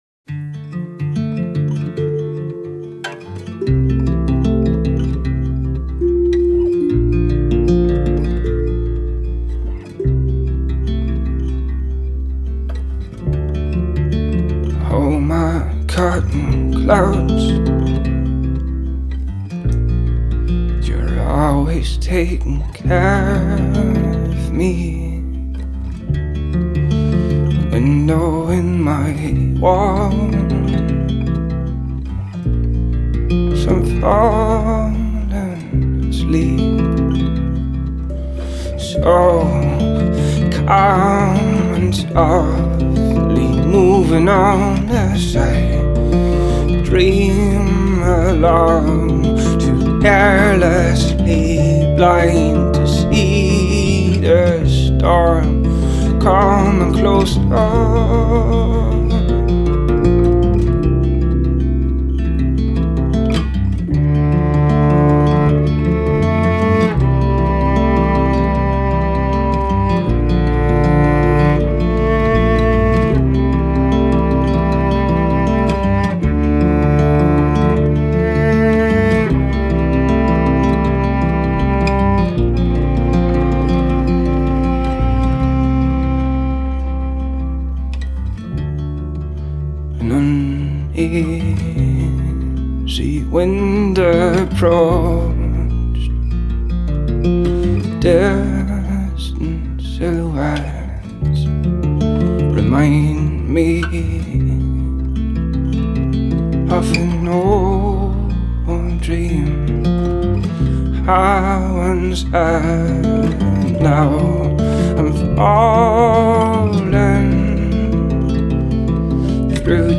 Epurée. Sincère. Mélancolique.
folk